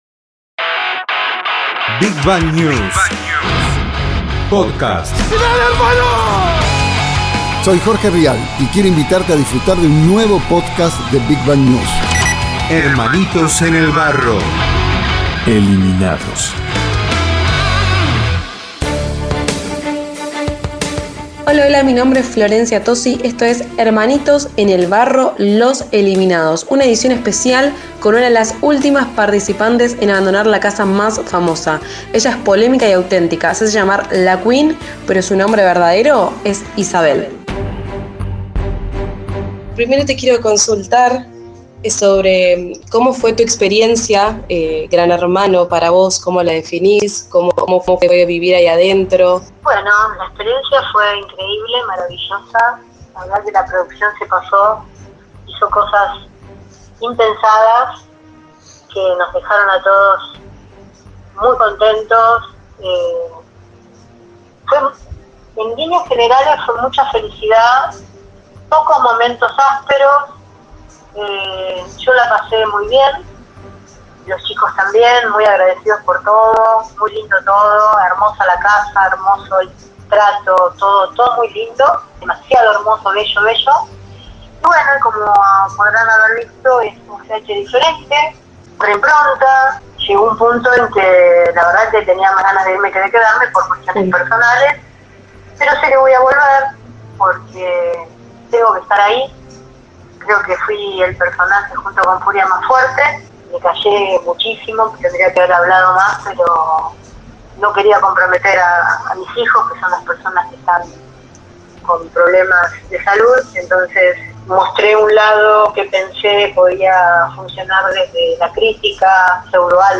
Un mano a mano imperdible